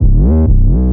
808 - SLIDE THRU.wav